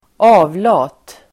Uttal: ['a:vla:t]
avlat.mp3